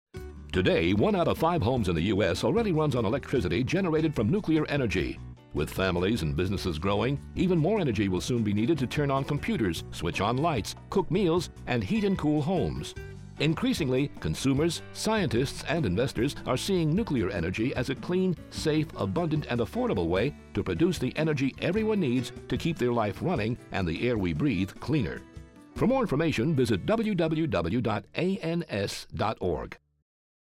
Public Service Announcements